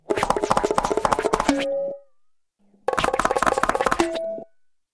На этой странице собрана коллекция забавных звуков мультяшного бега — от быстрых шлепающих шагов до комичных \
Мультяшный герой сбежал